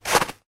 snowFootstep01.wav